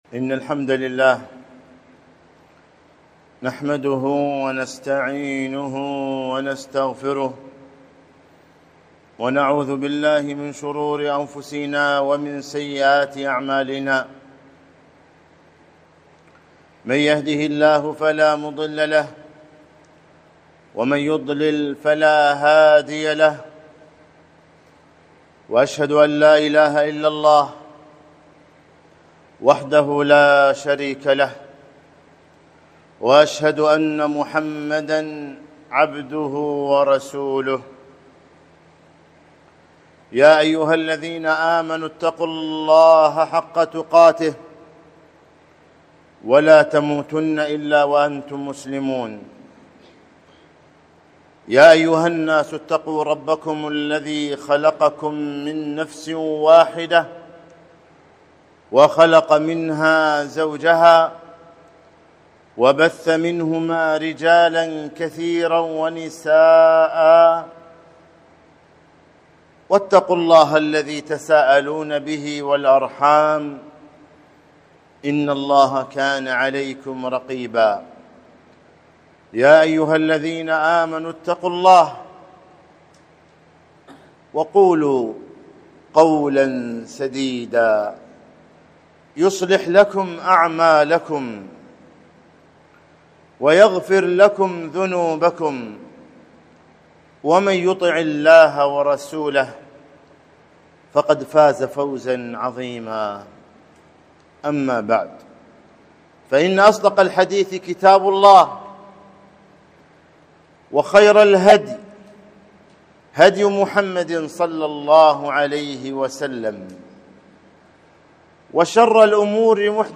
خطبة - مخموم القلب